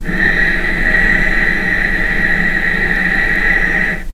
vc-B6-pp.AIF